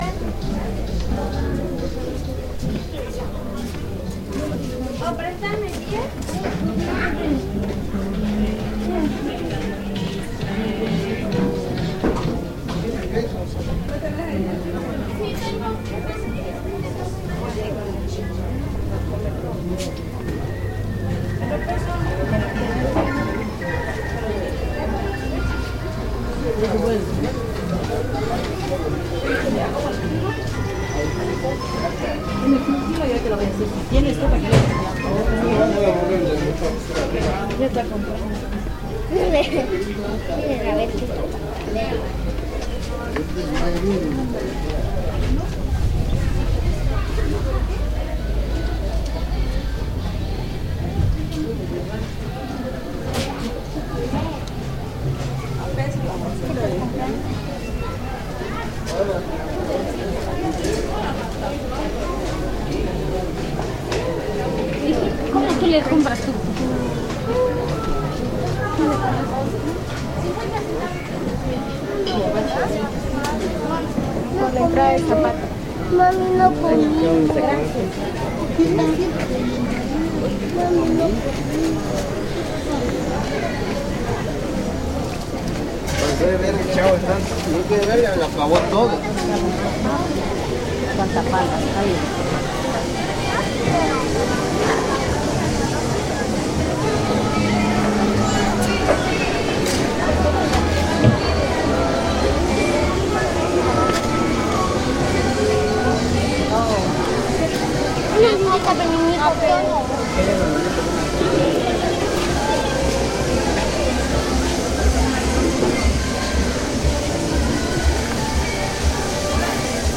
Asistí para cubrir el evento del 10 Festival Internacional Rosario Castellanos, estaba a cargo de la transmisión en vivo vía Internet de los eventos realizados en el Parque Central así que tuve tiempo de realizar un recorrido sonoro en el Mercado Municipal de la ciudad de Comitán de Domínguez en Chiapas, Mexico.
Lugar: Comitán de Domínguez, Chiapas; Mexico.
Equipo: Grabadora Sony ICD-UX80 Stereo, Micrófono de construcción casera ( más info ) Fecha: 2010-04-12 11:57:00 Regresar al índice principal | Acerca de Archivosonoro